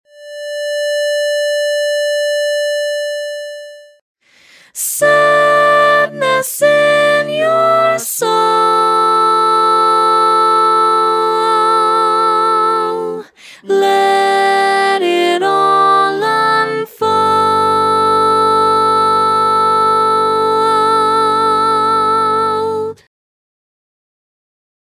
Key written in: D Minor
Learning tracks sung by